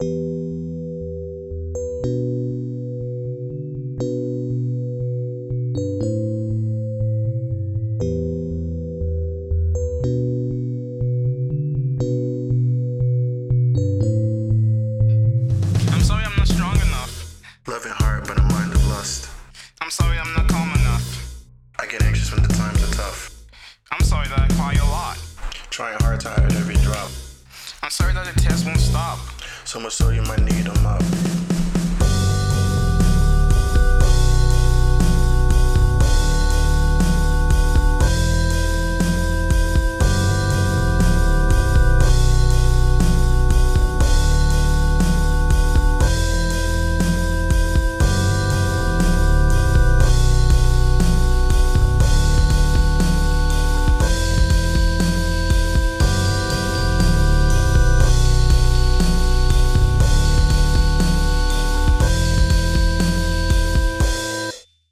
Poetry